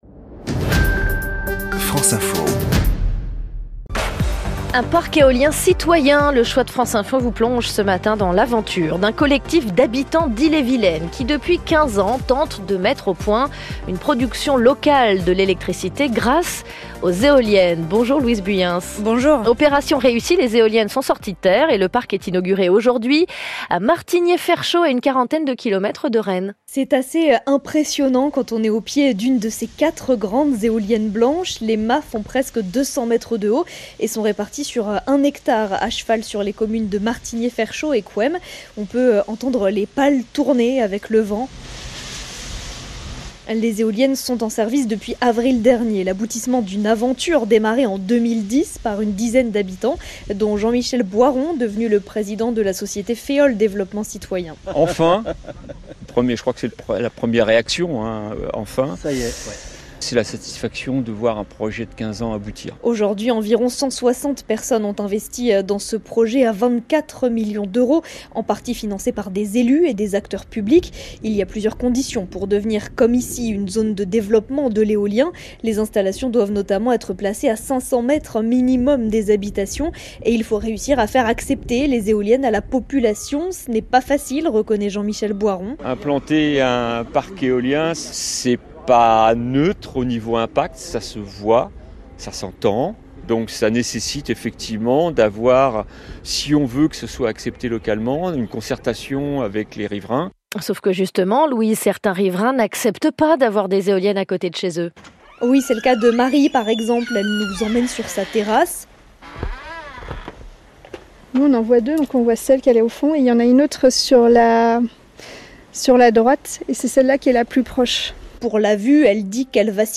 Dans sa matinale du vendredi 29 août, France Info a consacré un reportage exclusif aux éoliennes citoyennes de Féeole en Ille-et-Vilaine.